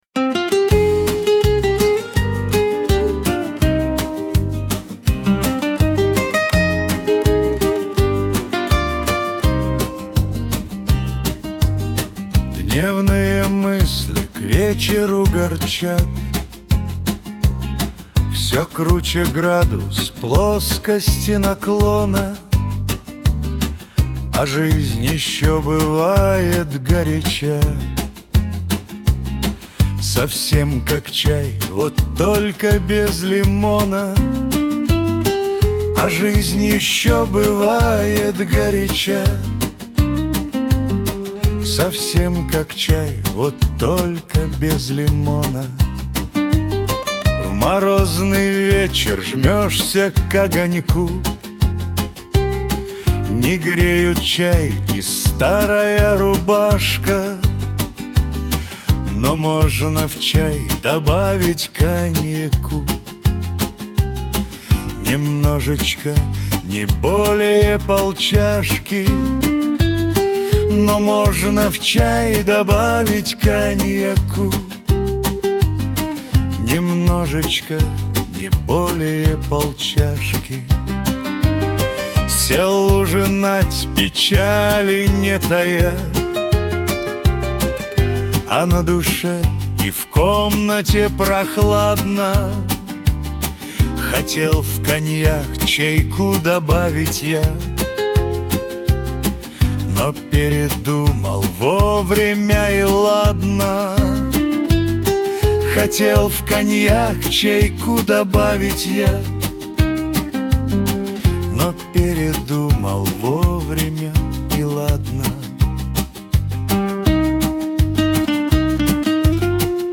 • Исполняет: Suno v4.5-all
• Жанр: Авторская песня